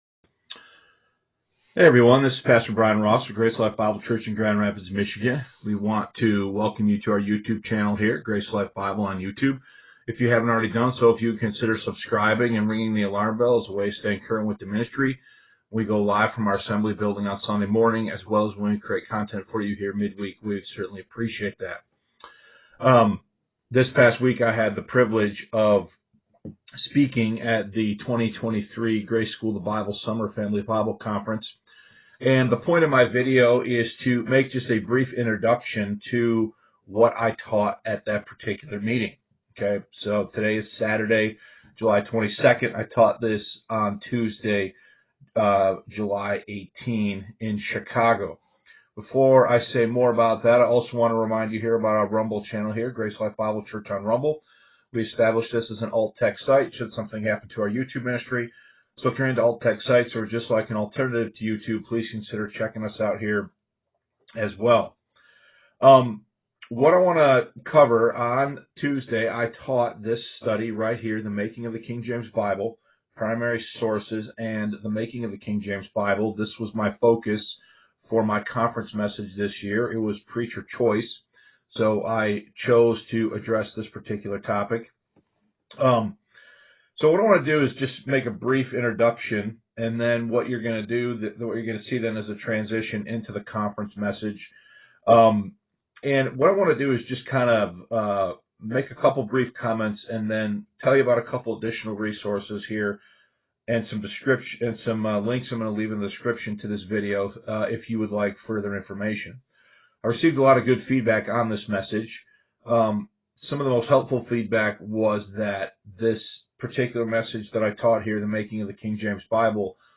The Making of the King James Bible (2023 Grace School of the Bible Summer Family Bible Conference)